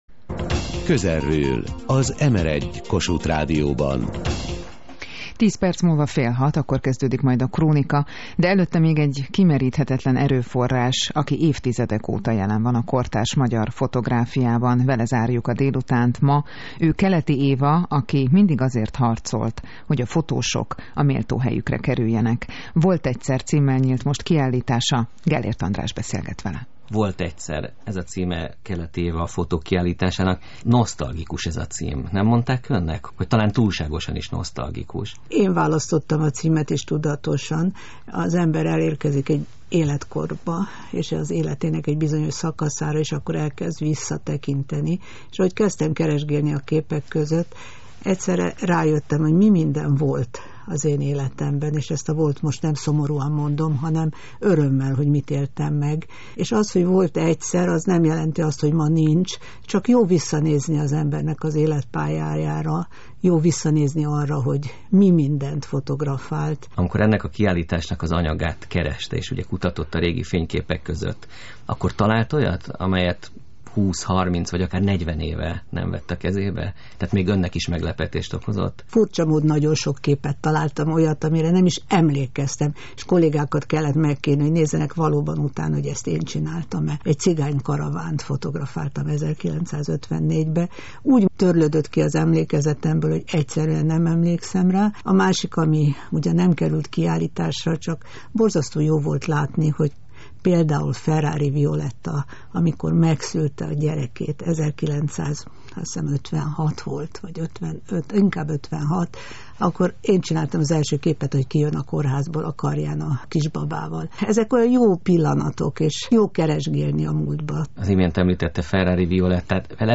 Beszélgetés Keleti Évával